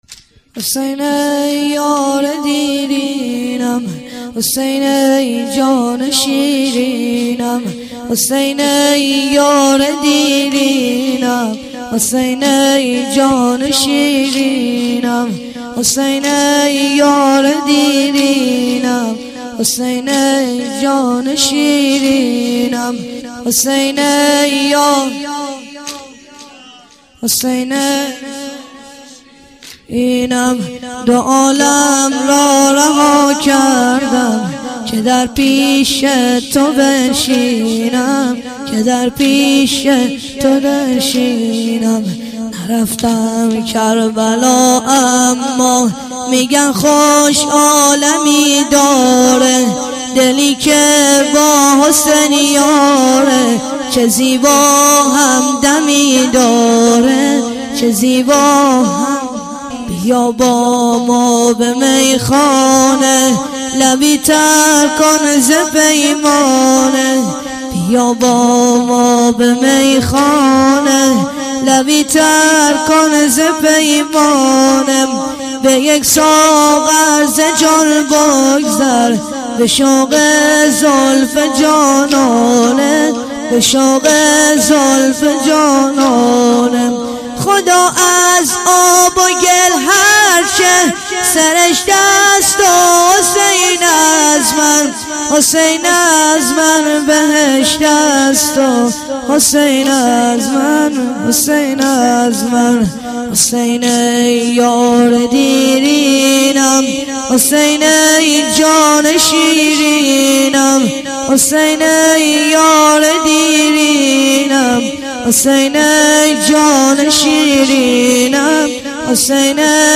چهار ضرب - محرم 1393